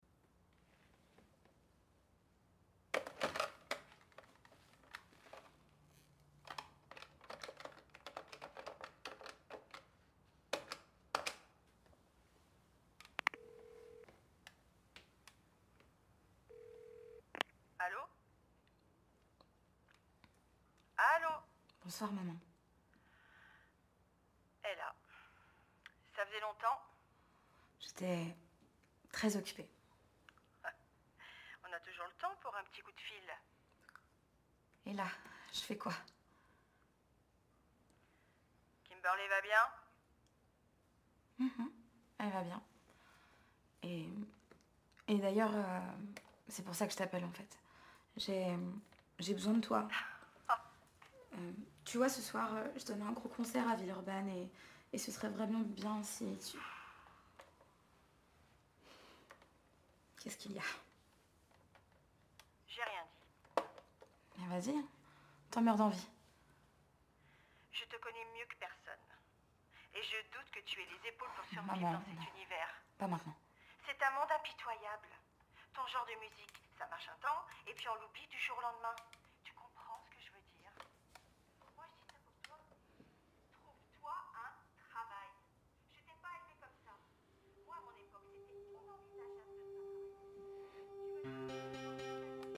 VOIX-OFF / ROLE D'UNE MERE
25 - 35 ans - Soprano